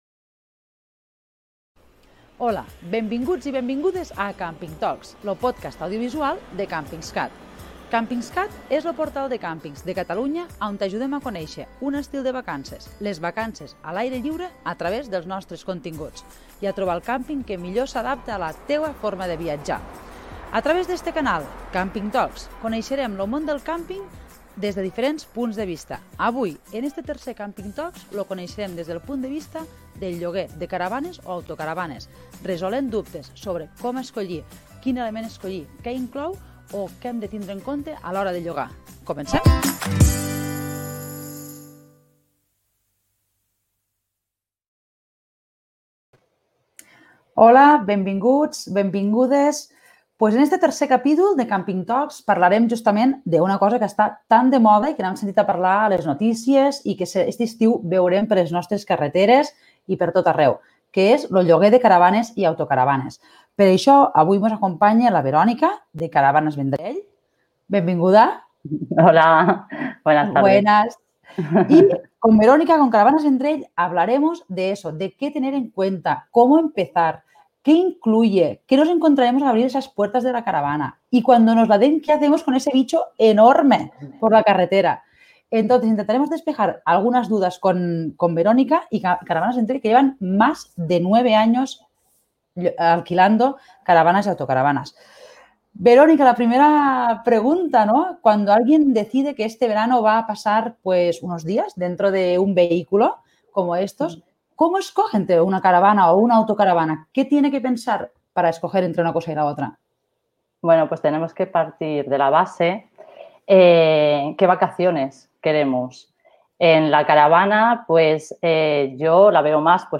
A través d’aquesta entrevista volem donar resposta als dubtes que puguin tenir moltes famílies o grups que estant pensant en llogar per primer cop.